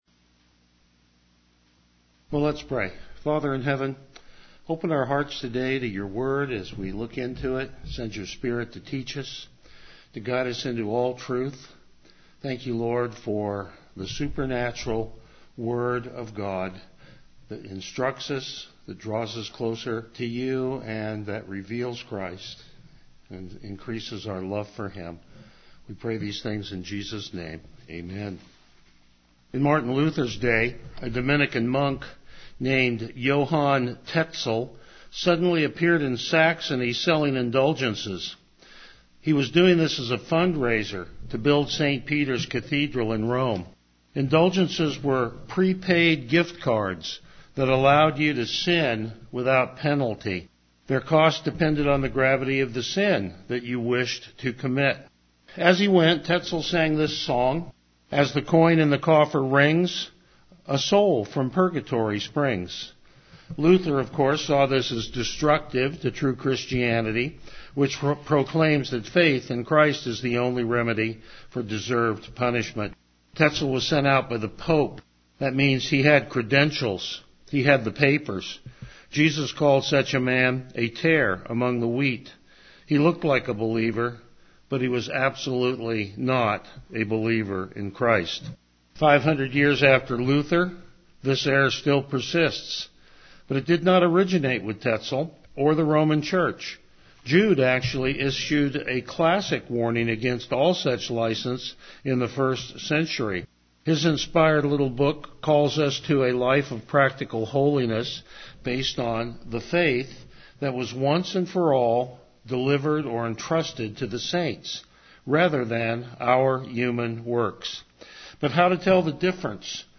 Passage: Jude 1-25 Service Type: Morning Worship Topics: Verse By Verse Exposition